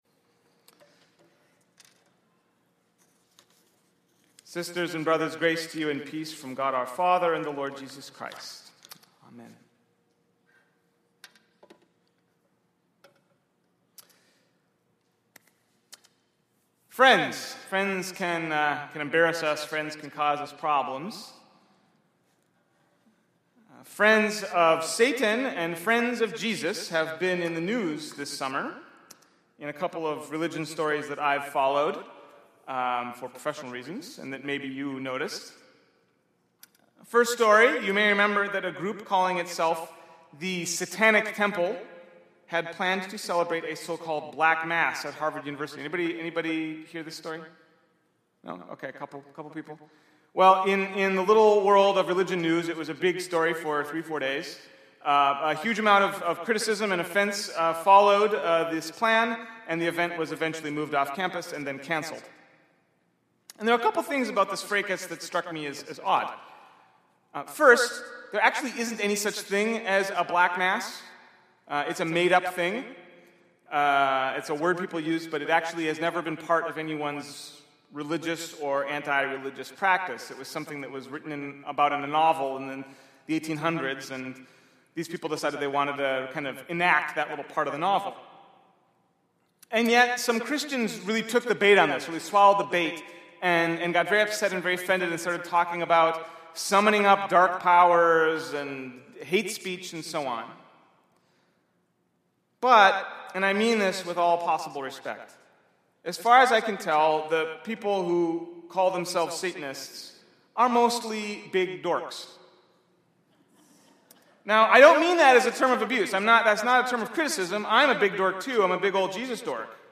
Sermon Playback: Speaking of Satan
I preached this sermon three years ago on this weekend’s text (Matthew 16):